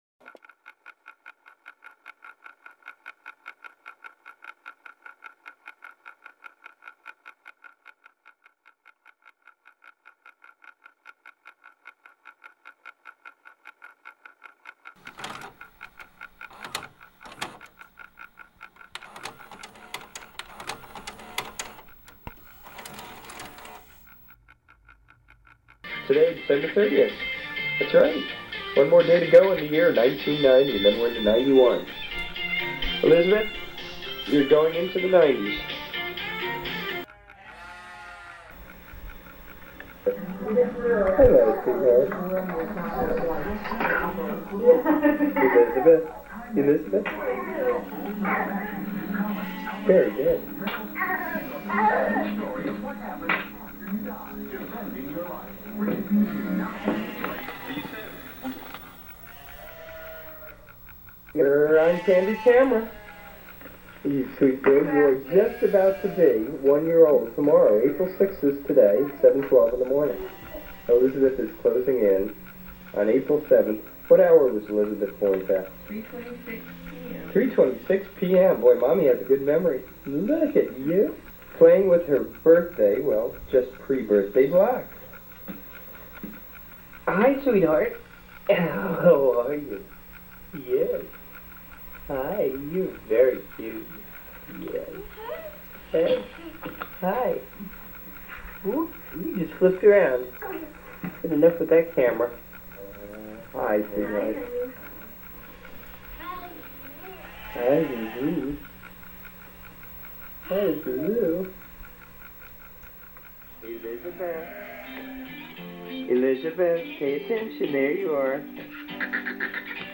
experimental music
phonography